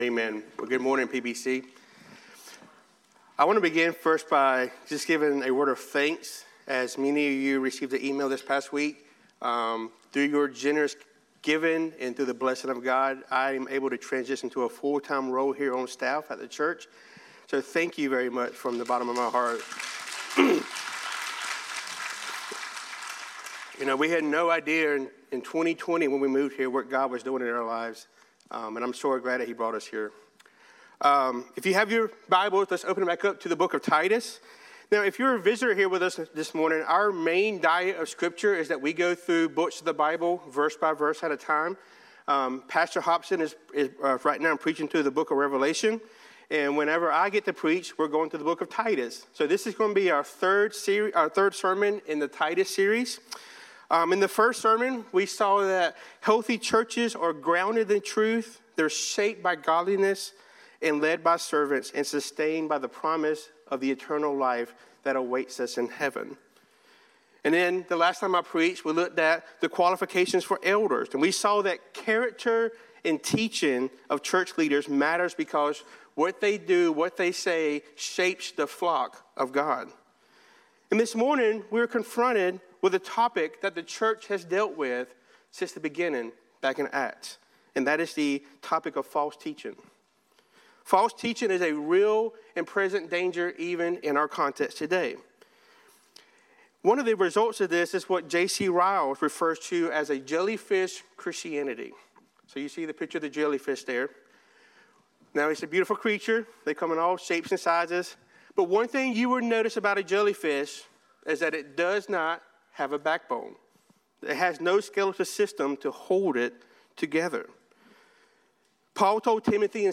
This Week's Sermon